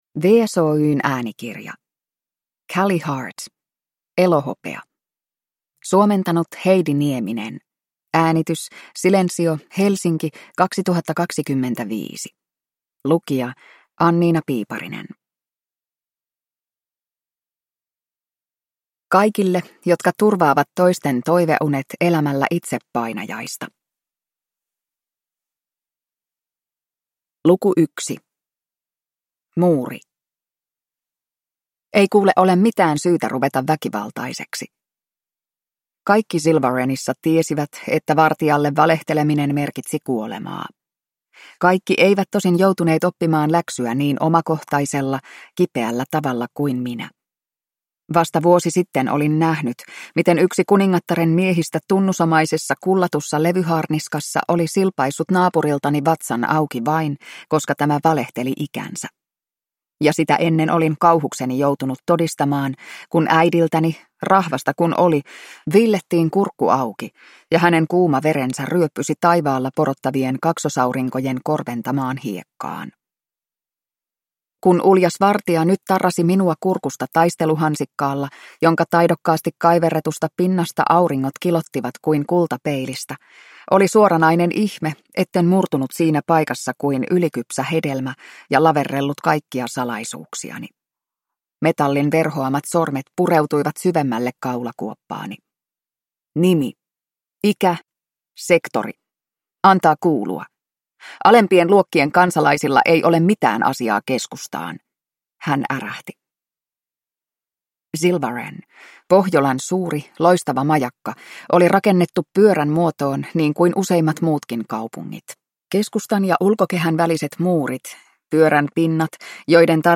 Elohopea – Ljudbok